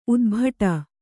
♪ udbhaṭa